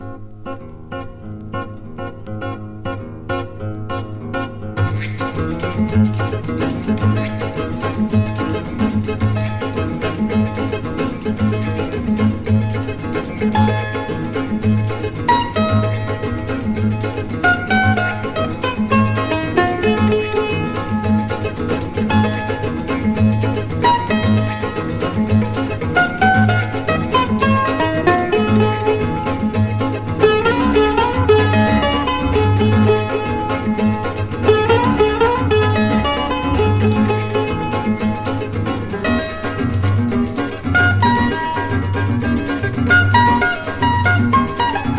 World, Latin, MPB, Samba, Choro　Brazil　12inchレコード　33rpm　Mono